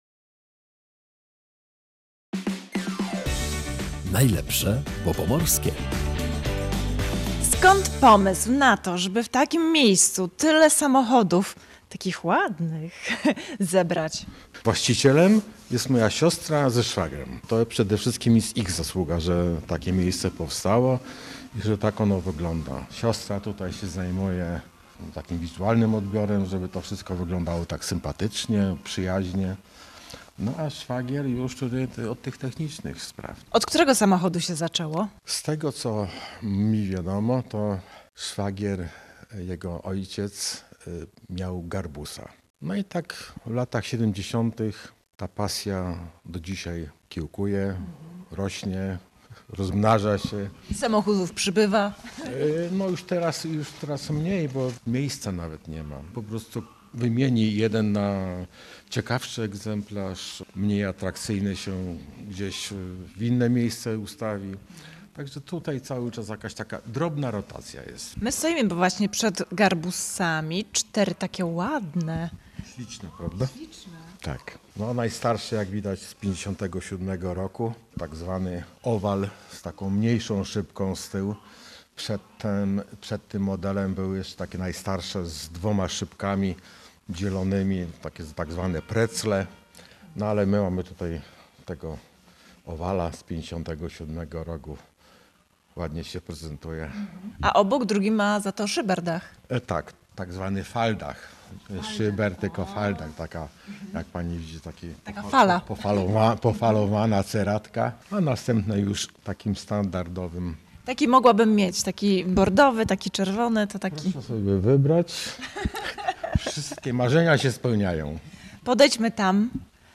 W audycji „Najlepsze, bo Pomorskie” zajrzeliśmy do Pępowa. Jest tam Muzeum Volkswagena.